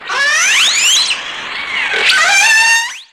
SI2 WHALES0D.wav